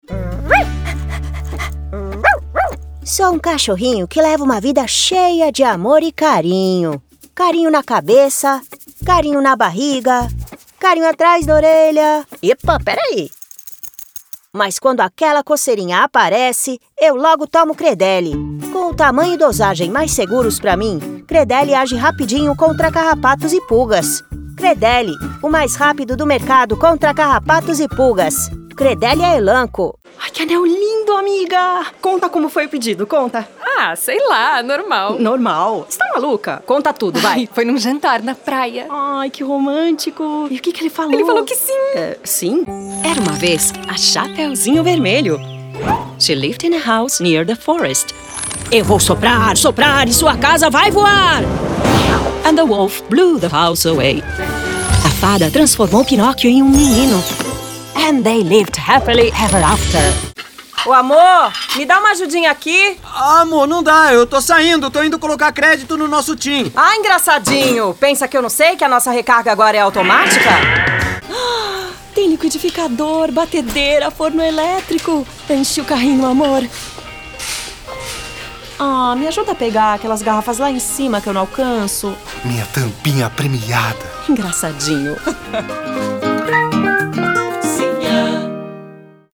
Natürlich, Vielseitig, Warm
Persönlichkeiten